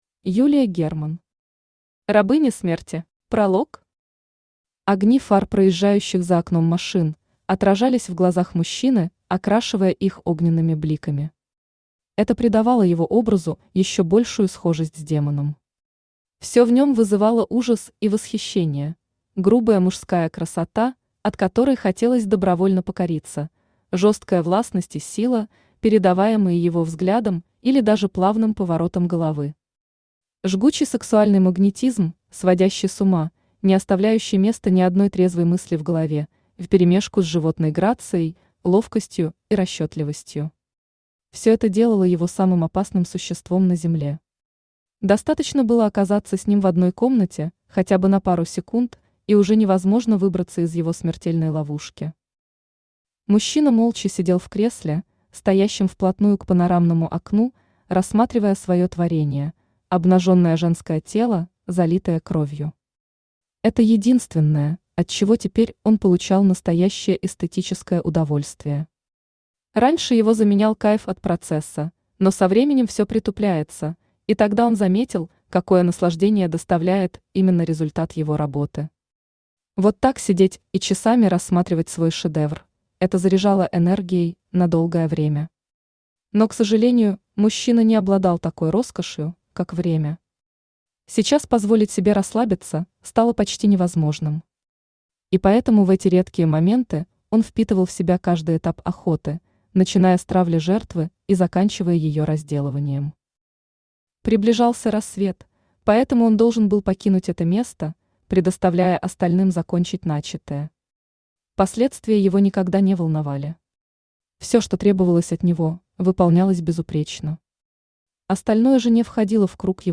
Аудиокнига Рабыня Смерти | Библиотека аудиокниг
Aудиокнига Рабыня Смерти Автор Юлия Михайловна Герман Читает аудиокнигу Авточтец ЛитРес.